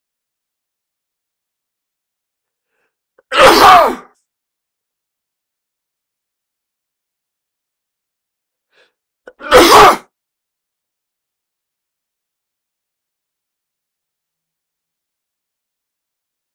دانلود آهنگ عطسه 4 از افکت صوتی انسان و موجودات زنده
دانلود صدای عطسه 4 از ساعد نیوز با لینک مستقیم و کیفیت بالا
جلوه های صوتی